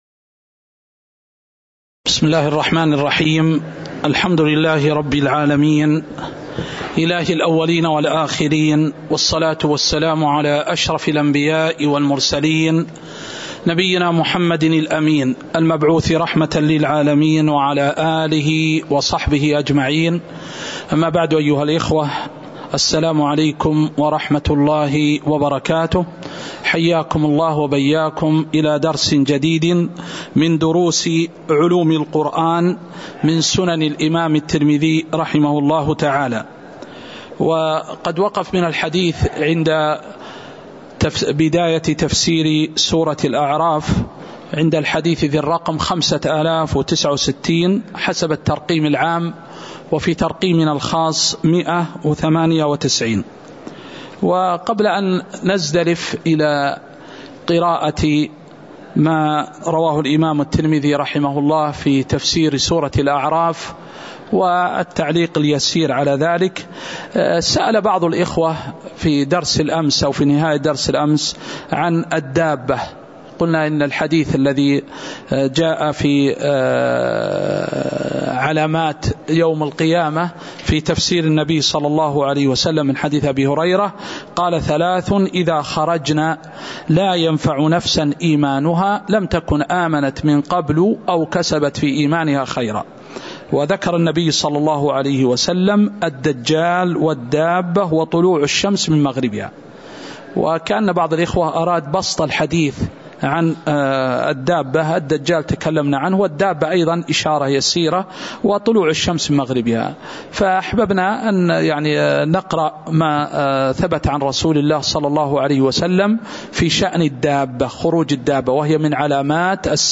تاريخ النشر ١٦ ربيع الثاني ١٤٤٣ هـ المكان: المسجد النبوي الشيخ